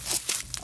Berry & Bush sounds in wwise
Bush2.wav